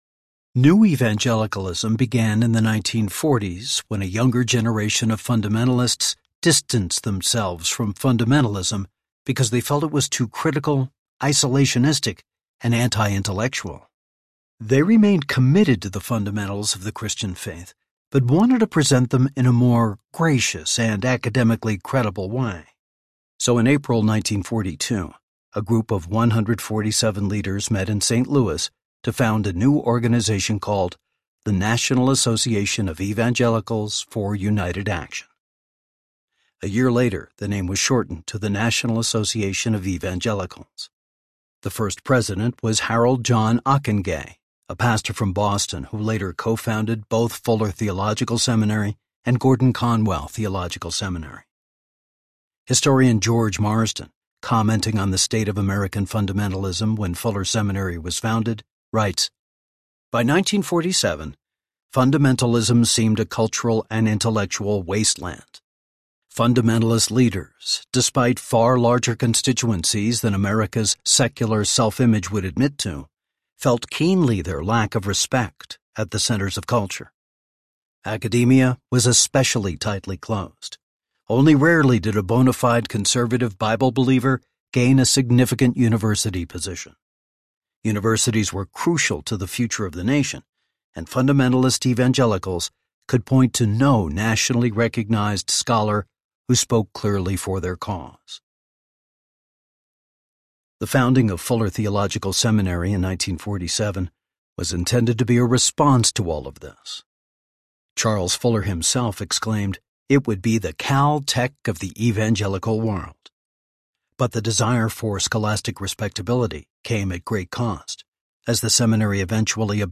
Right Thinking in a Church Gone Astray Audiobook
Narrator
7.6 Hrs. – Unabridged